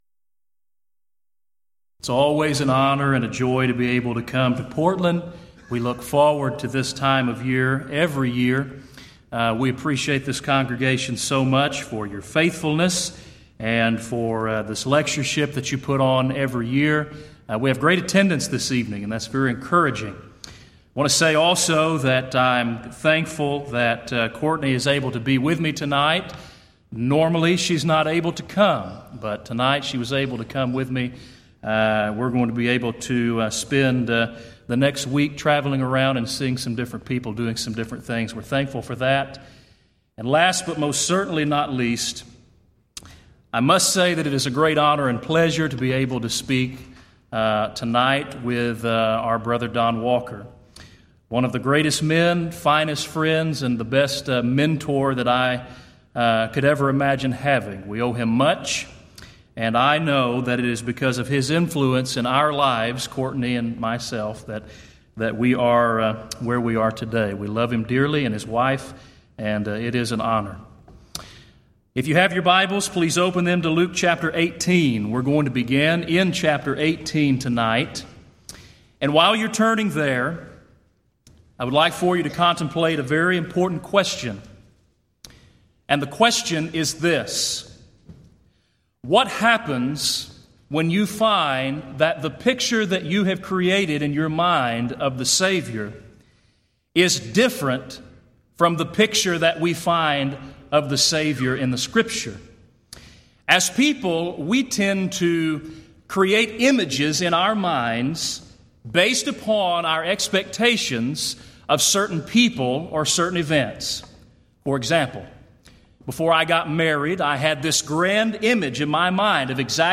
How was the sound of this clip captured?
Event: 21st Annual Gulf Coast Lectures